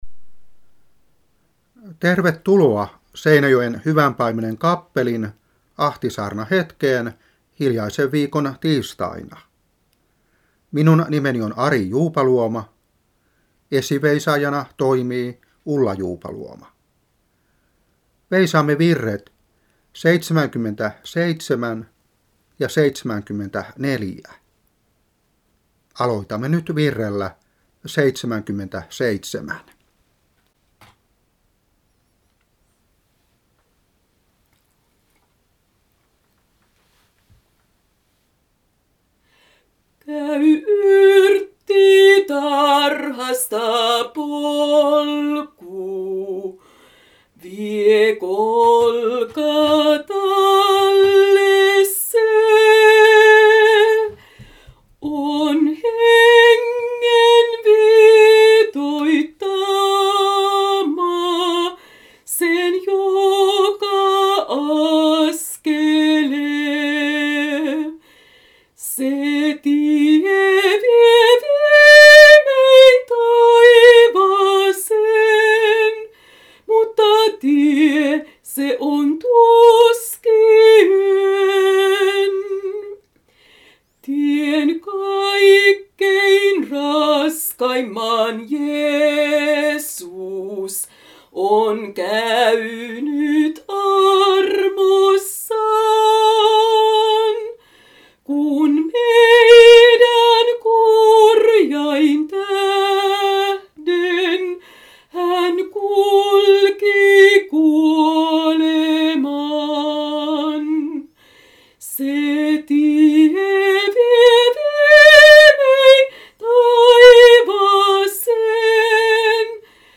Saarna 1994-3.